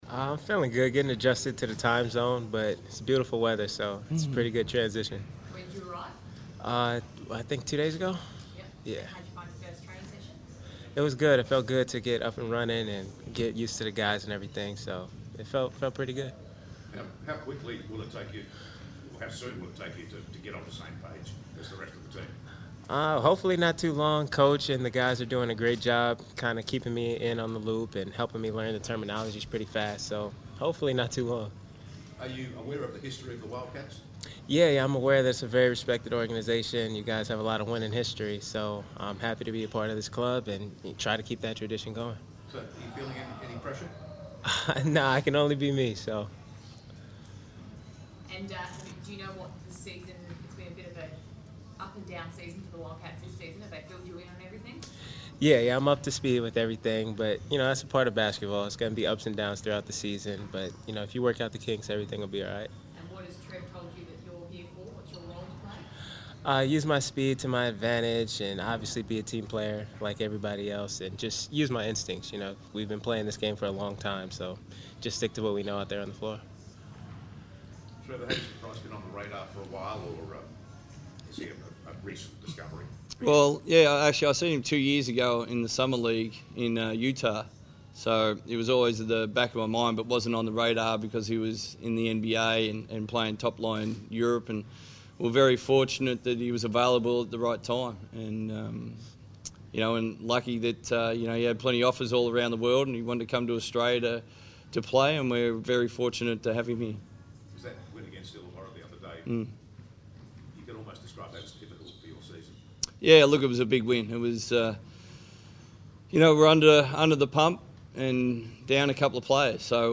Bryce Cotton's first press conference - 3 January 2017